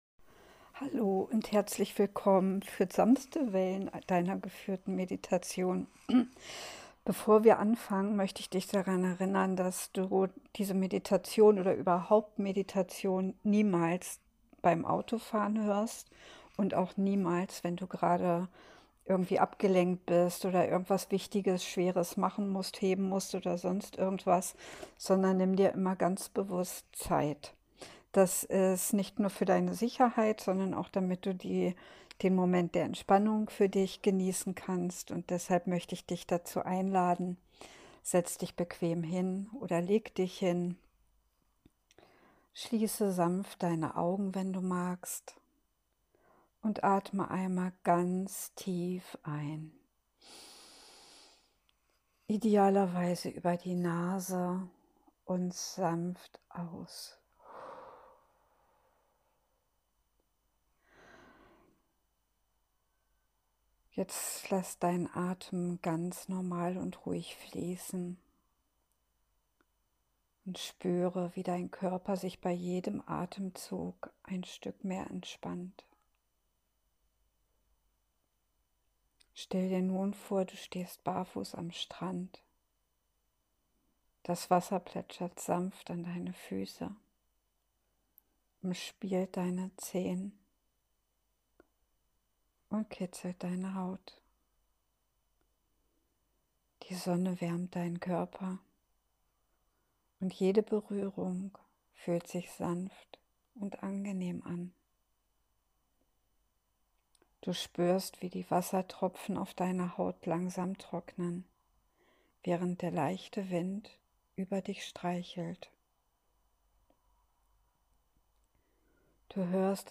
Meine geführte Meditation „Sanfte Welle“ ist ein kleiner Rückzugsort, den du jederzeit aufsuchen kannst – wann immer du Ruhe, Frieden und innere Leichtigkeit spüren möchtest.
Lass dich vom Plätschern der Wellen, der warmen Sonne und dem leisen Gesang der Möwen tragen.
sanfte_Wellen_am_Strand.mp3